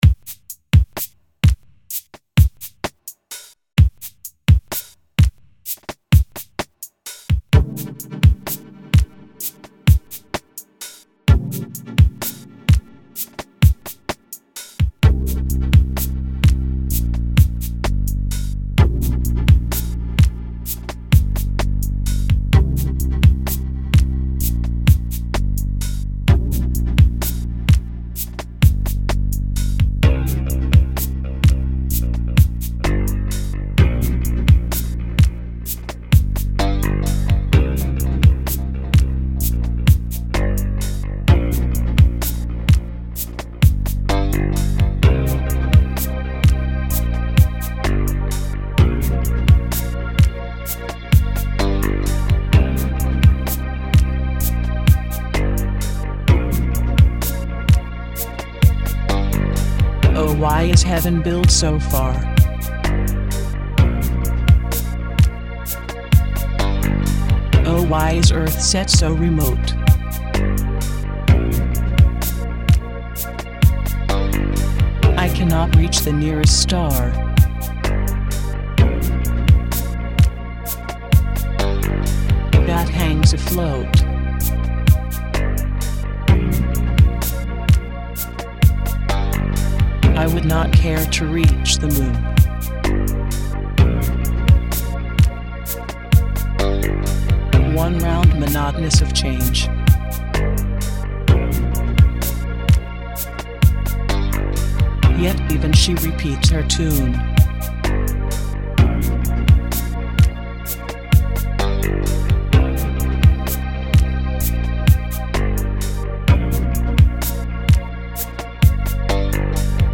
Genre Postrock